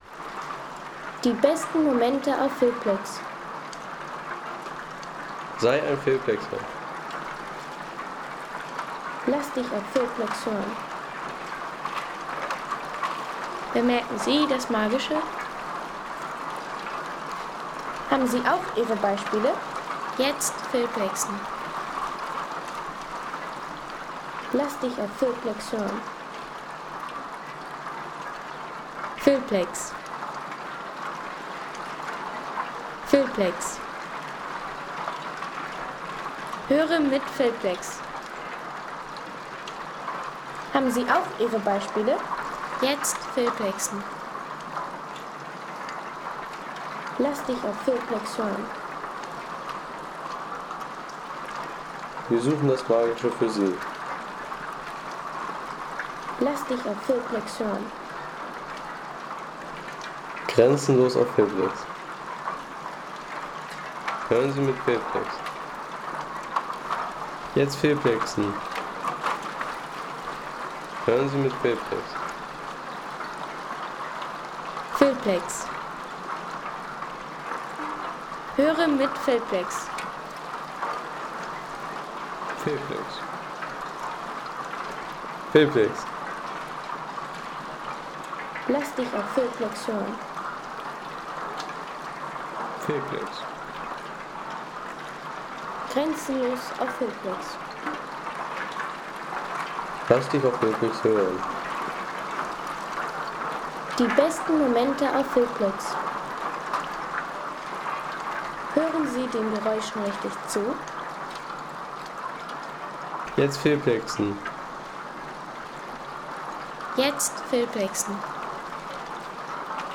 Treibende Eisschollen
Das Rascheln des Treibeises – Naturklang am Sankelmarker See.